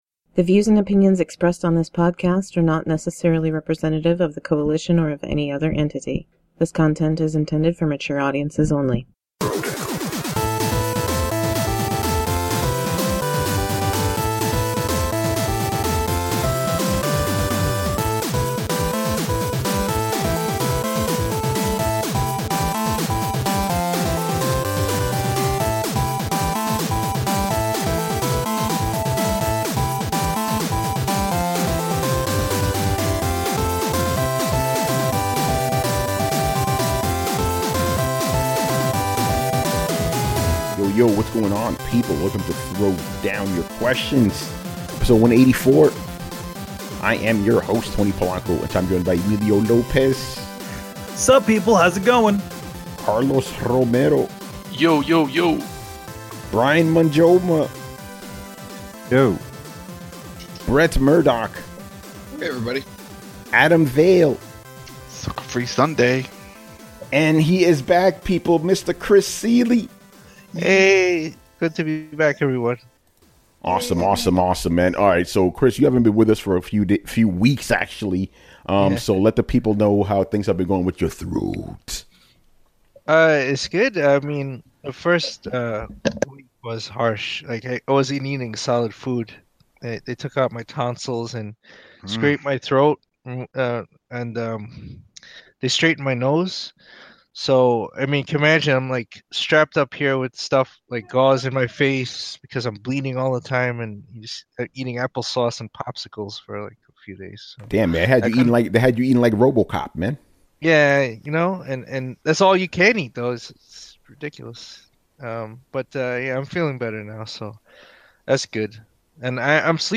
Welcome to another episode of Throwdown Your Questions! On this show, we answer all of your Video Game related questions.